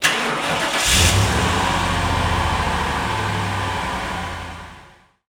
Car Start 2 Sound
transport